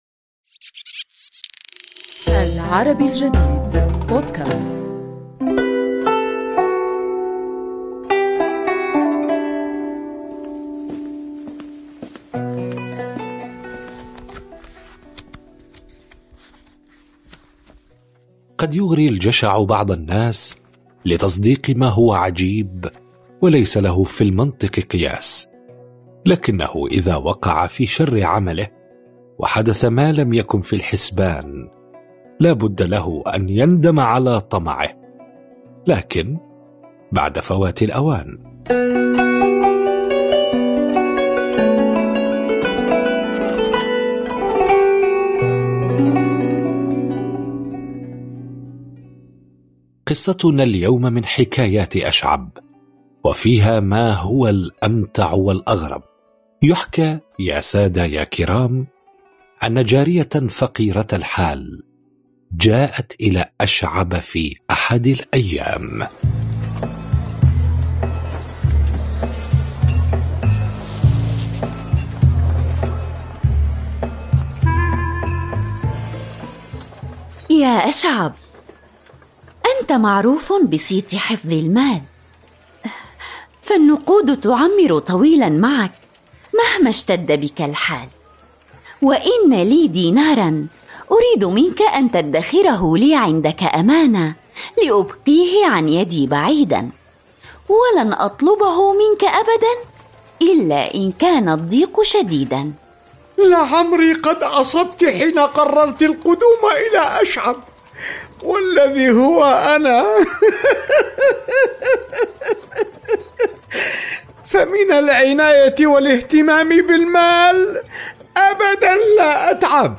نروي لكم اليوم في بودكاست "حكواتي" عن أشعب وحكايته مع الجارية، بقصة فيها ما هو الأمتع والأغرب.